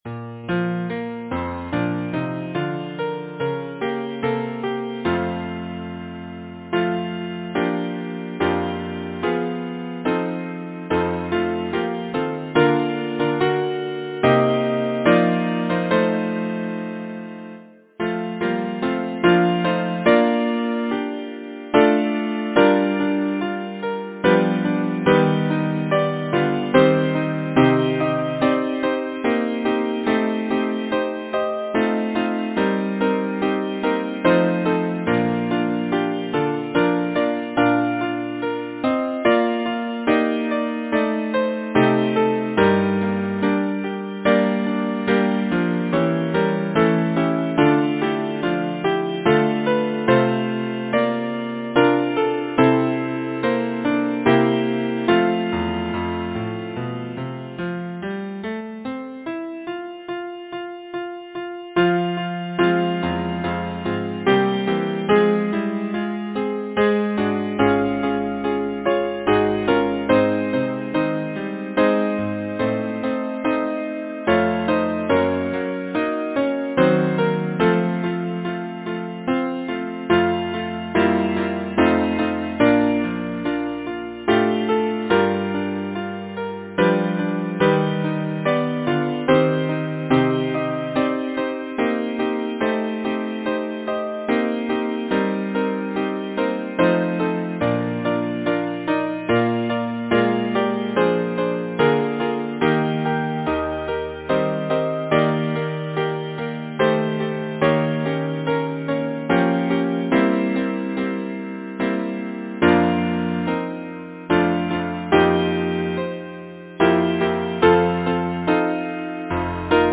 Title: He prayeth best, who loveth best Composer: Albert W. Platte Lyricist: Samuel Taylor Coleridge Number of voices: 4vv Voicing: SATB Genre: Secular, Partsong
Language: English Instruments: Piano